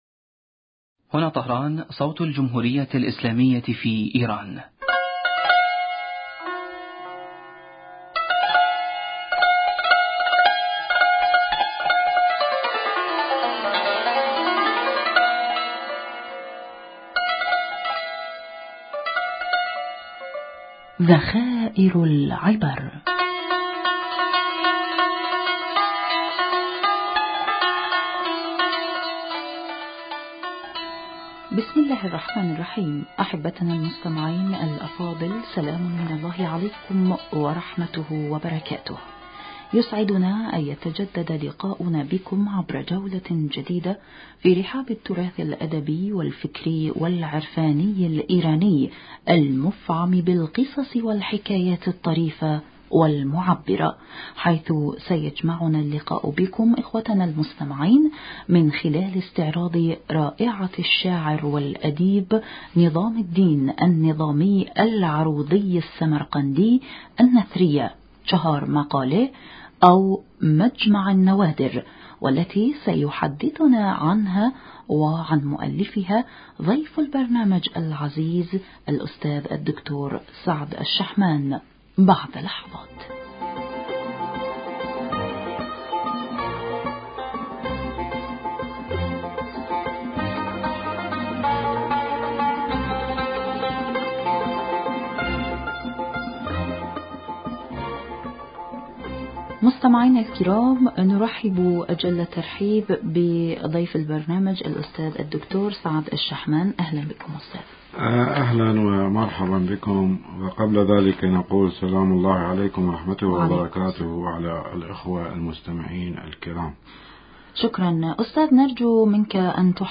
ضيف البرنامج عبر الهاتف